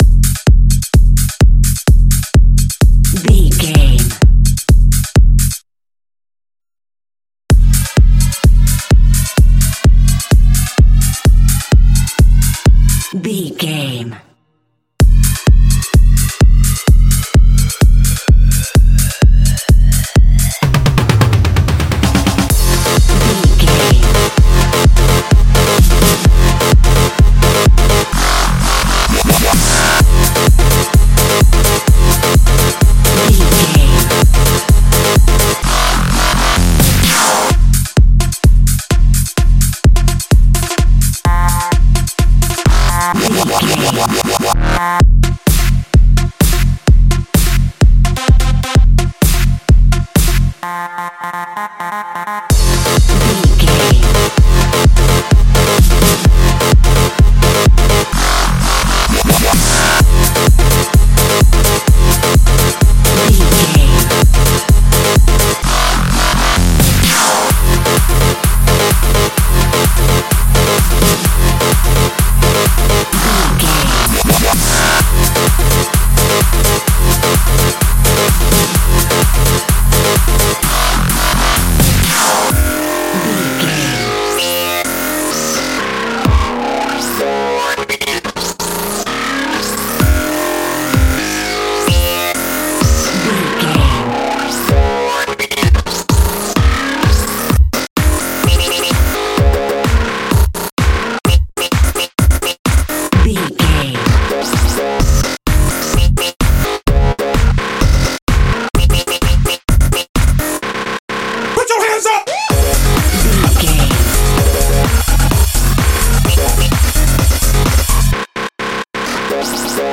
Dubstep From the Club.
Aeolian/Minor
Fast
aggressive
dark
groovy
futuristic
frantic
drum machine
synthesiser
breakbeat
energetic
synth leads
synth bass